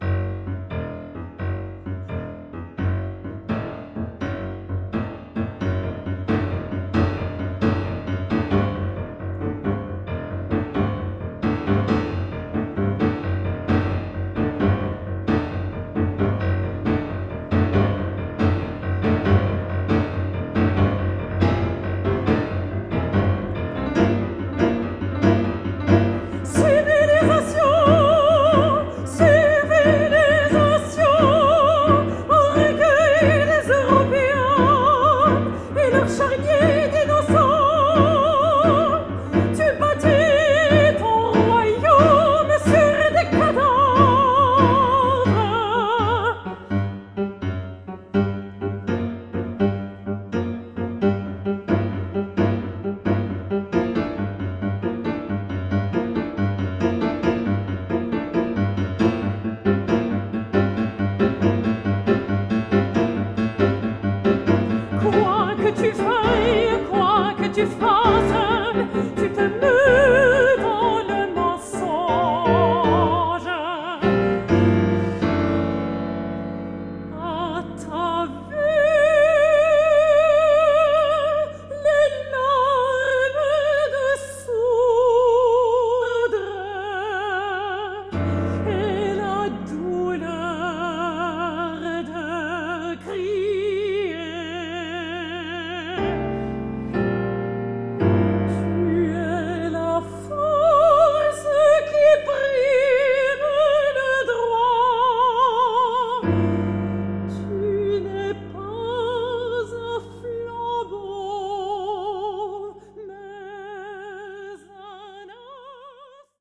mezzo-soprano
piano
Enregistré au Studio Stephen Paulello, Villethierry